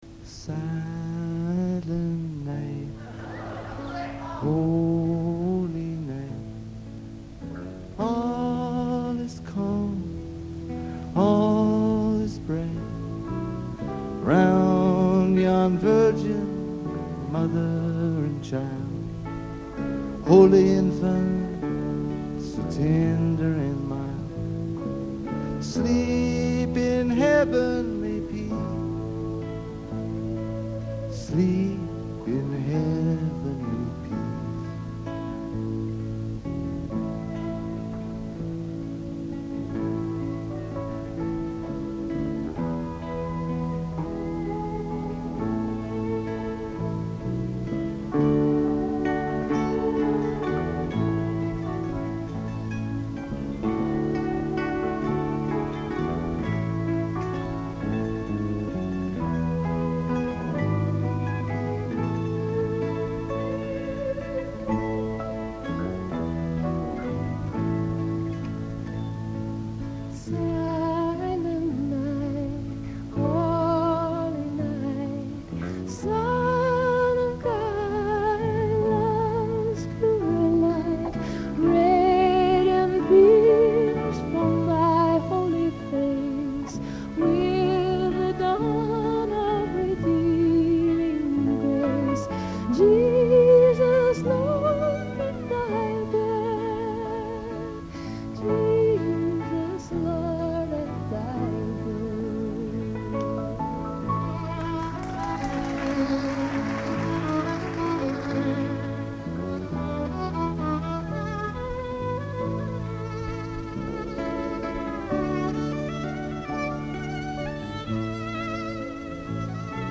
violin, in the Dome Theatre, Brighton